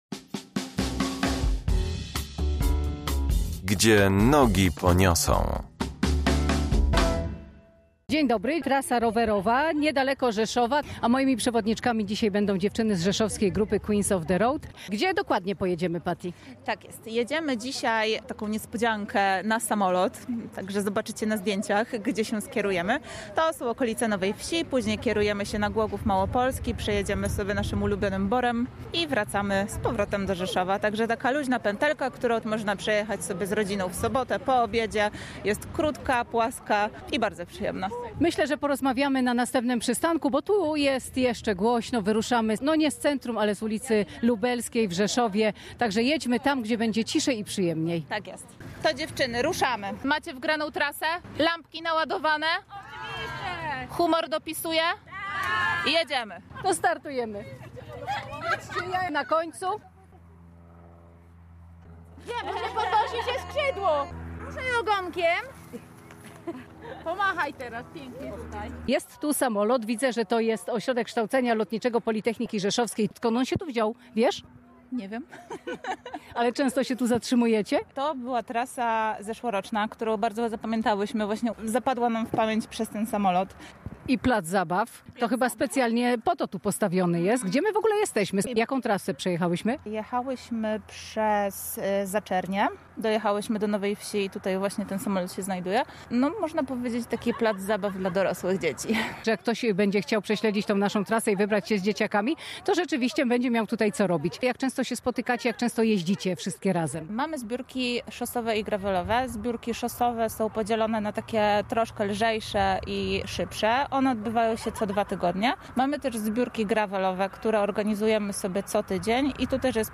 Trasa rowerowa z Rzeszowa do Głogowa Małopolskiego okazała się nie tylko urokliwa, ale też pełna niespodzianek. Tym razem przewodniczkami są dziewczyny z rzeszowskiej grupy Queens of the Road.